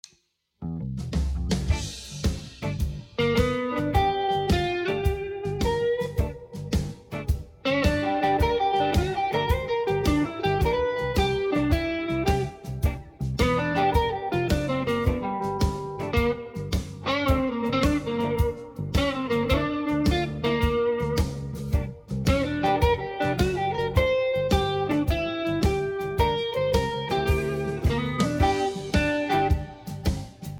habe jetzt mal aufgenommen was ich eigentlich spiele wenn ich Improvisiere.
Leider klingt das ganze noch nicht sonderlich schön:cautious:
Die erste Phrase (Takt 2 und 3) ist sparsam, das finde ich gut, aber danach wirds abrupt hektisch, viele Noten und viele Sprünge auf- und abwärts.
Was etwas stört, und wo Verbesserungsbedarf ist: Du spielst im Moment G7/G-Dur/Moll-Pentatonik über alles.
Vom Timing finde ich dein Spiel klasse.